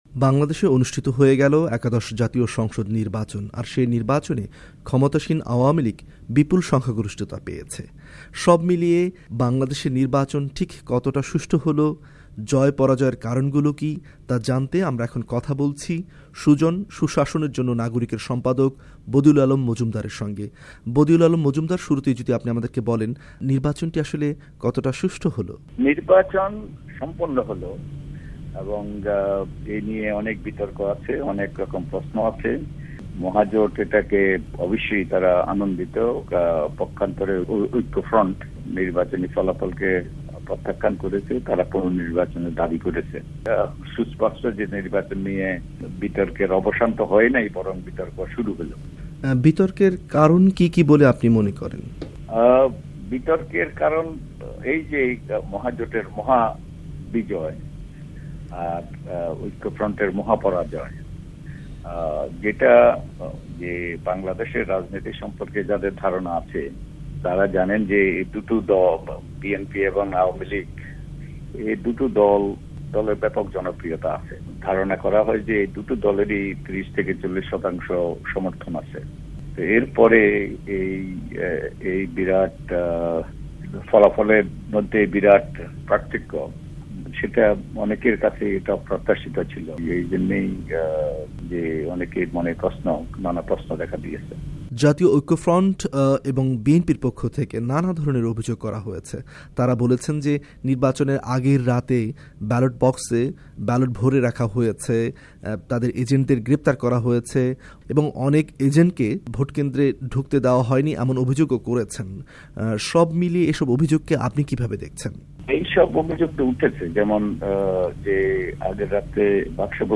ভয়েস অফ আমেরিকার ওয়াশিংটন স্টুডিও থেকে তার সঙ্গে কথা বলেন